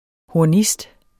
Udtale [ hoɐ̯ˈnisd ]